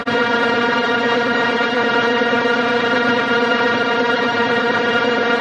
描述：古典乐|欢快
标签： 弦乐器 贝司
声道立体声